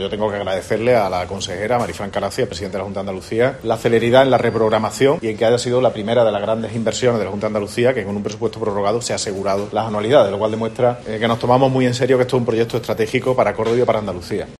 En declaraciones a los periodistas, el regidor ha agradecido a la consejera de Fomento, Marifrán Carazo, y al presidente de la Junta, Juanma Moreno, "la celeridad en la reprogramación y que haya sido la primera de las grandes inversiones de la Junta que con un presupuesto prorrogado ha asegurado las anualidades", de modo que "demuestra que esto es un proyecto estratégico para Córdoba y Andalucía", ha subrayado.